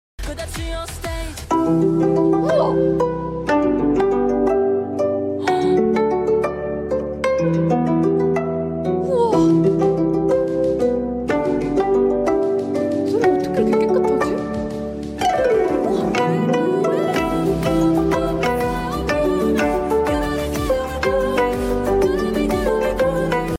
가야금 커버